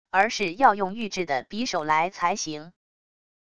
而是要用玉制的匕首来才行wav音频生成系统WAV Audio Player